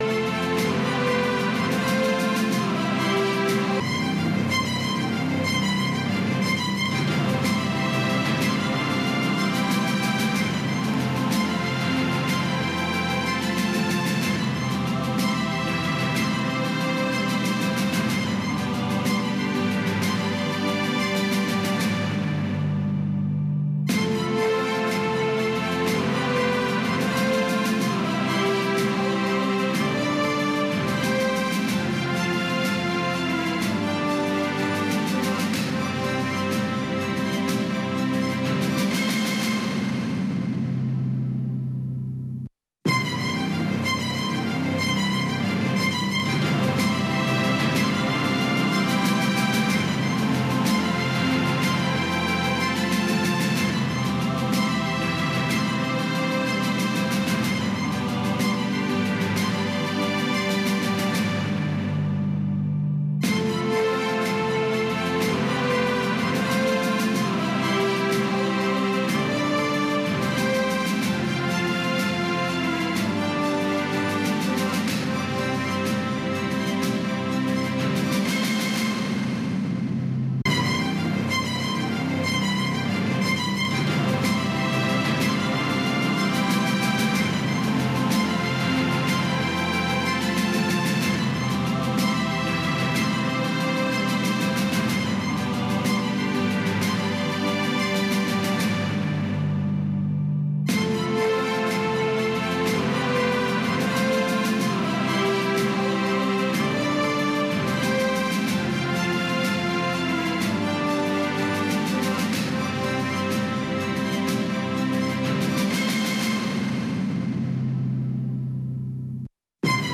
د وی او اې ډيوه راډيو سهرنې خبرونه چالان کړئ اؤ د ورځې د مهمو تازه خبرونو سرليکونه واورئ.